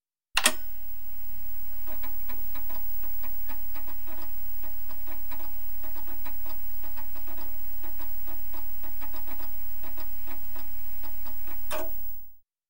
disc-seek-test.mp3